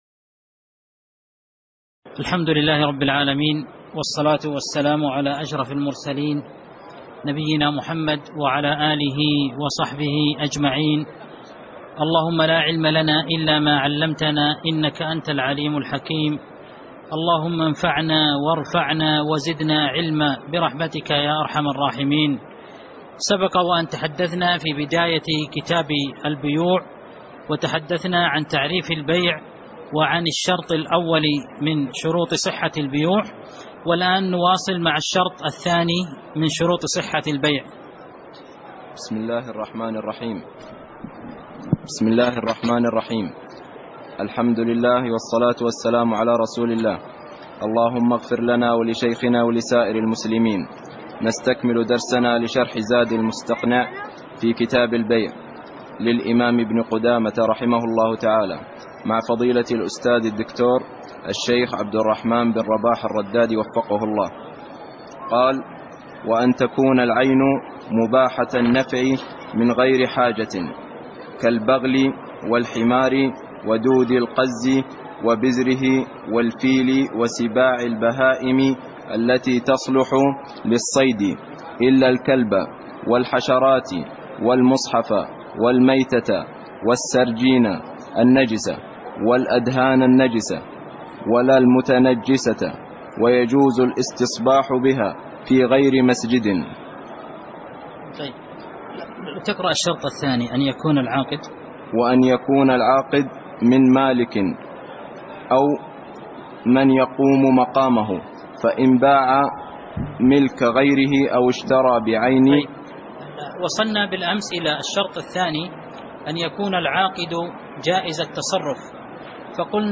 تاريخ النشر ١٥ جمادى الآخرة ١٤٣٨ هـ المكان: المسجد النبوي الشيخ